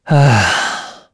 Esker-Vox_Sigh_kr.wav